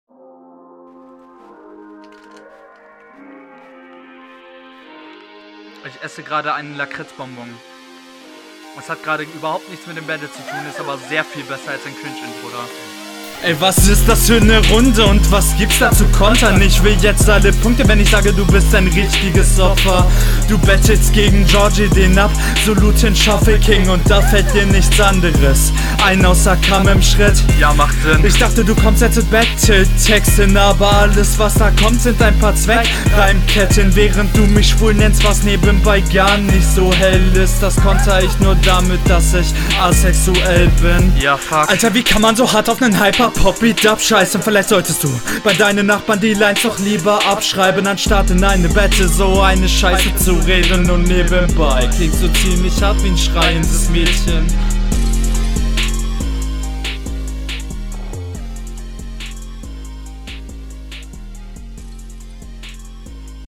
Flowlich eigentlich ganz solide nur ab & an stockst du ein wenig, Gegnerbezug ist stark …